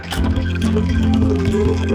The examples build on generated samples that are around 4 seconds long.
The samples that Engram generates do not hold on their own as actual music.
engram_nature_sounds.wav